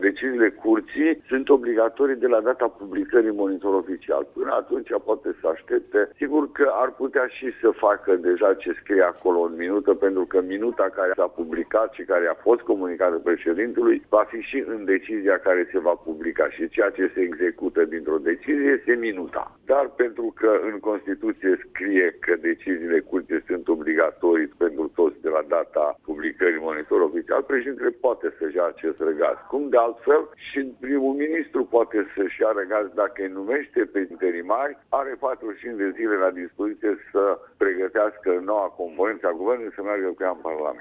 Fostul preşedinte al Curţii Constituţionale, Augustin Zegrean, a explicat, la Radio România Actualităţi, care este termenul limită până la care atât şeful statului cât şi premierul trebuie să pună în aplicare decizia de ieri a CCR: